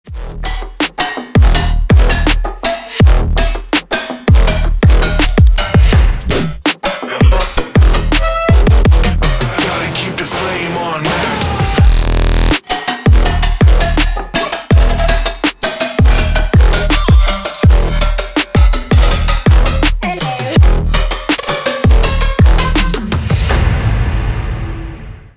Trap рингтоны